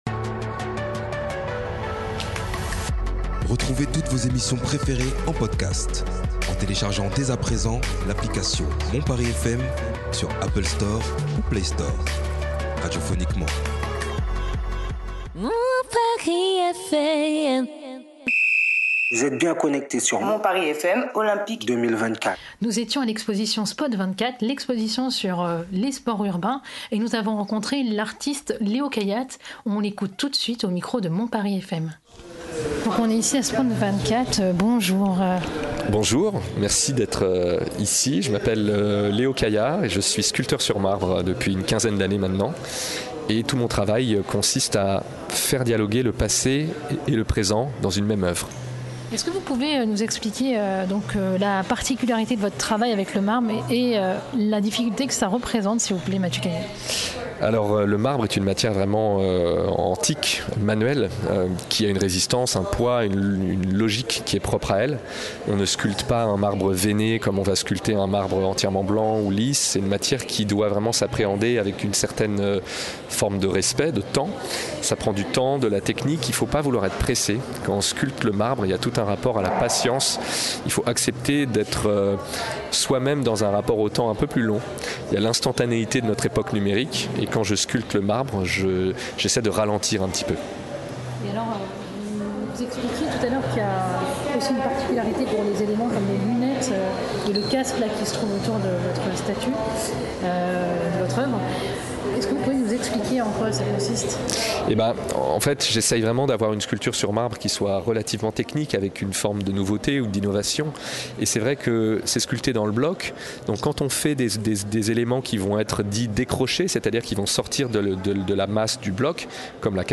SPOT 24 : Olympisme et Culture urbaine entretien